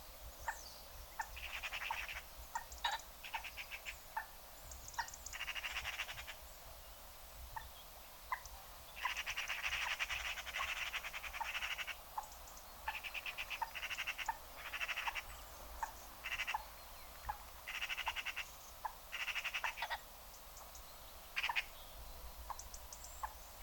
Pita de auga
Gallinula chloropus
Canto